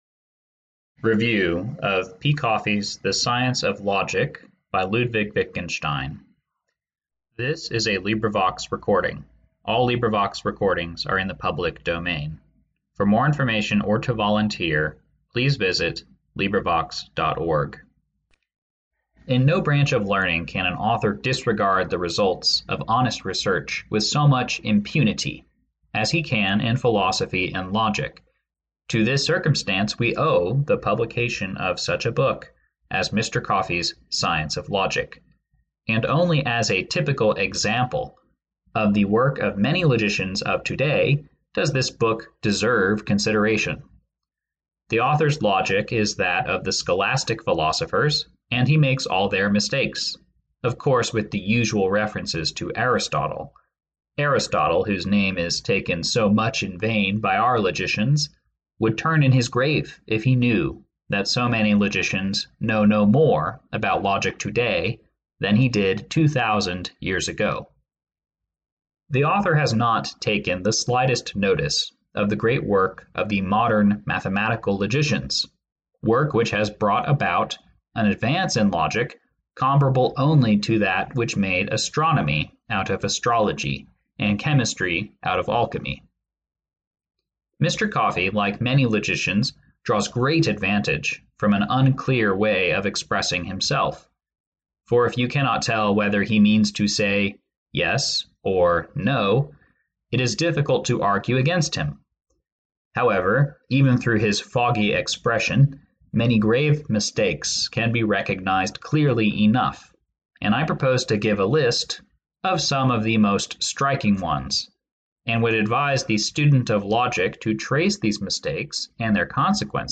Review of P. Coffey, “The Science of Logic” (audiobook)
Review of P. Coffey, The Science of Logic Audiobook
Wittgenstein, Review of P. Coffey, The Science of Logic audiobook.mp3